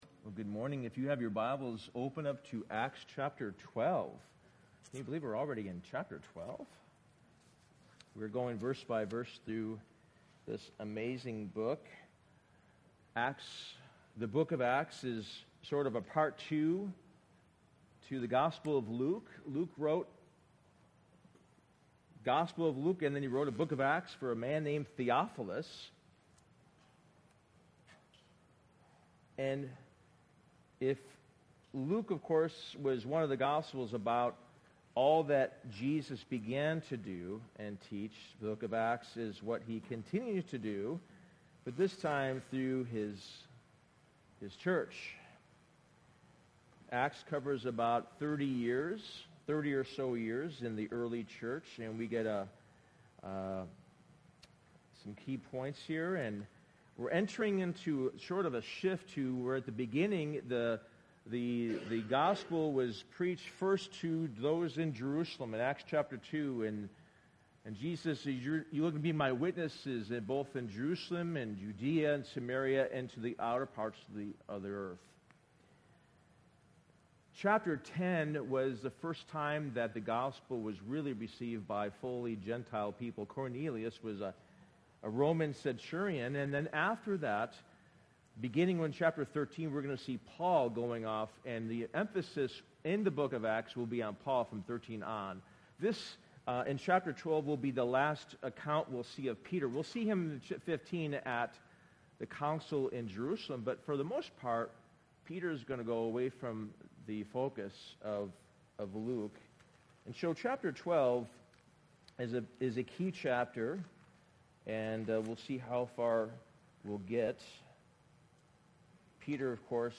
Sermon: The Power of a Praying Church